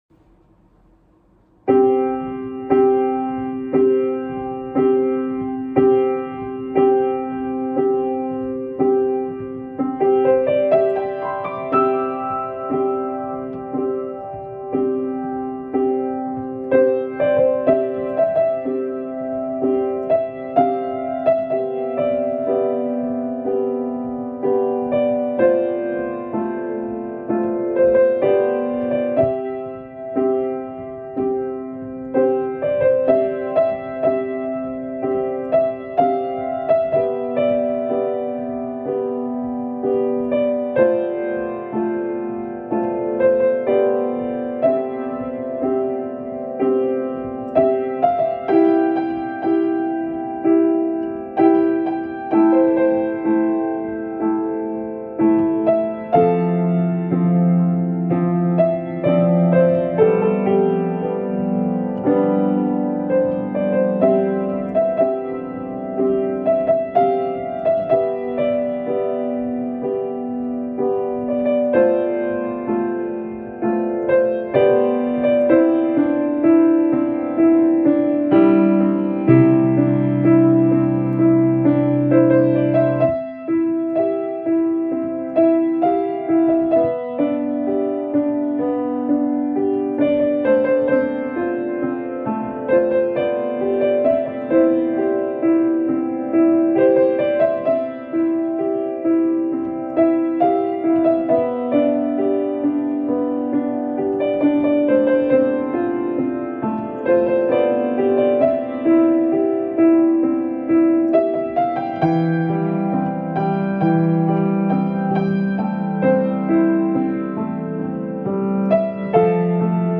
ほぼ初見程の練習ですが弾いてみました。